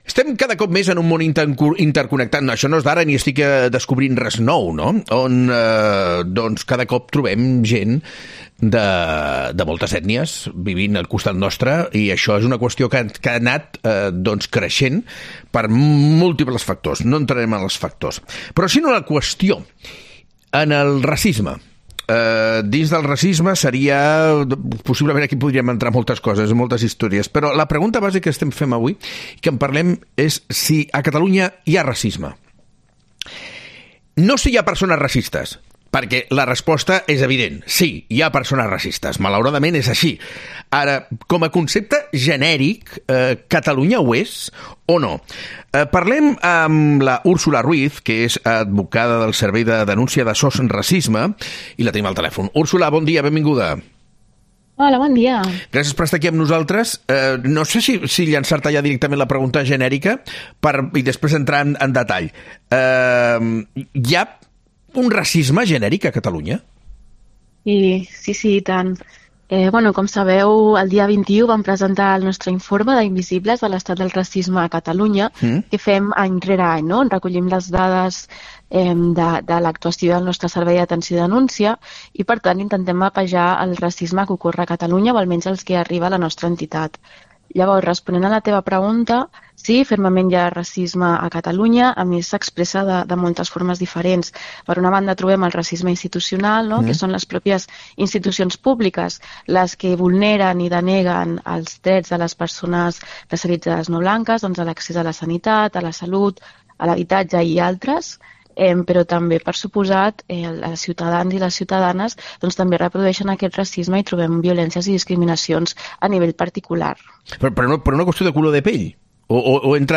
En una entrevista exclusiva